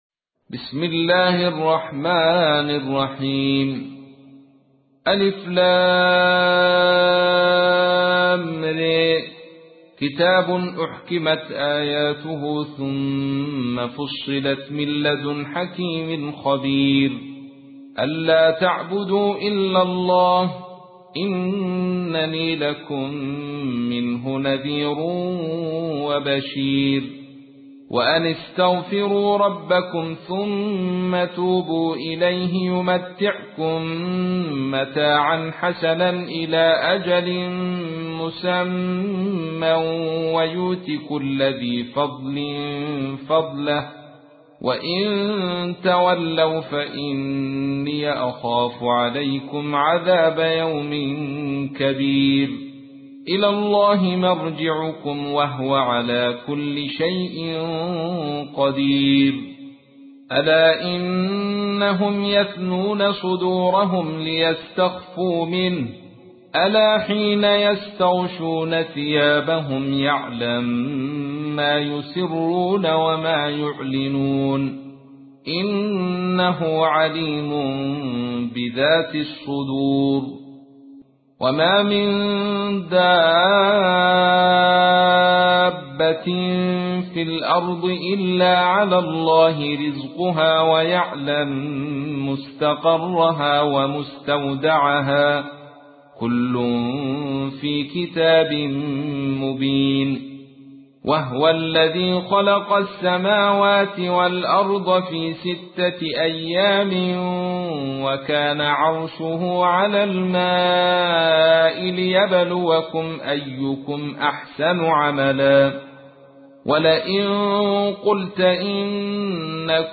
سورة هود / القارئ عبد الرشيد صوفي / القرآن الكريم / موقع يا حسين